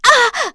Erze-Vox_Damage_01.wav